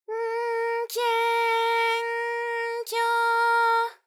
ALYS-DB-001-JPN - First Japanese UTAU vocal library of ALYS.
ky_N_kye_N_kyo.wav